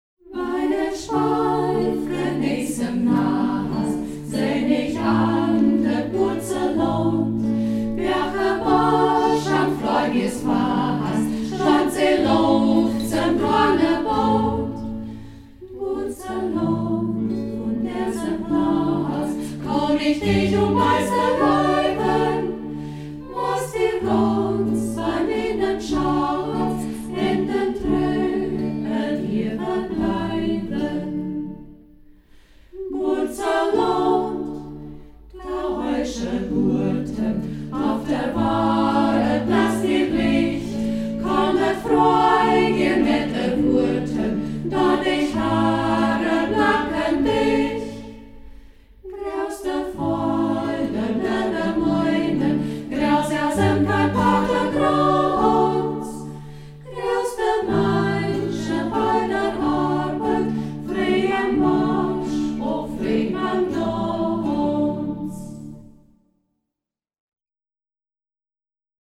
Ortsmundart: Zeiden